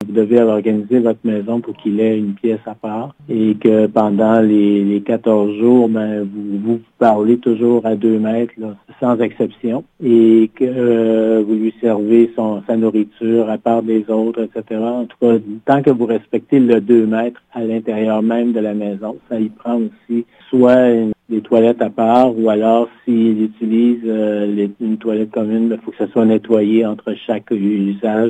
Le directeur régional de la Santé publique, Yv Bonnier-Viger